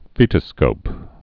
(fētə-skōp)